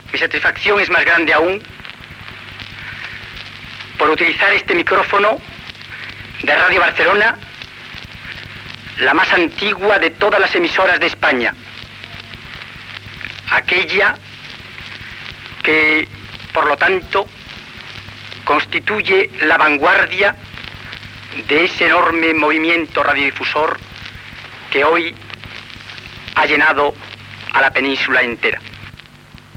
Paraules de Jesús Suevos, Director General de Radiodifusión en la seva visita a Ràdio Barcelona.
Fragment extret d'"Historia de Radio Barcelona 1924-1974" (1974).